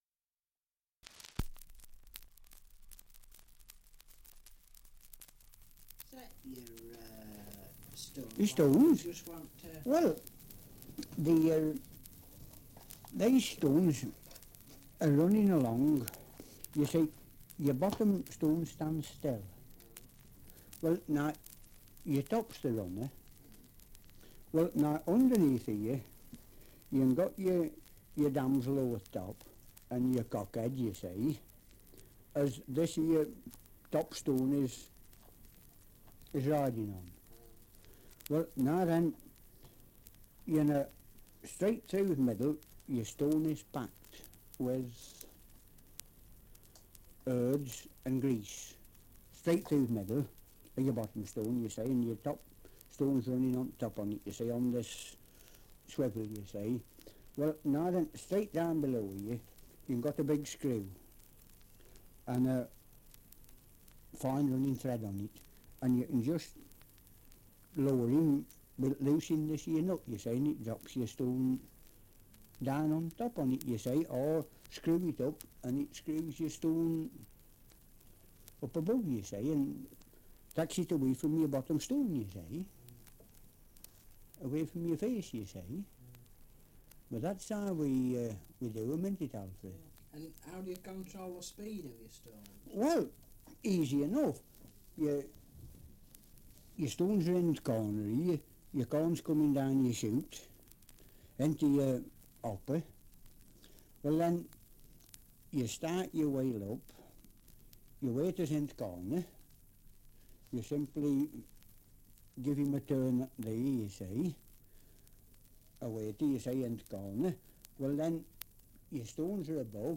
Survey of English Dialects recording in Swettenham, Cheshire
78 r.p.m., cellulose nitrate on aluminium